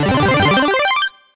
four_lines_complete.ogg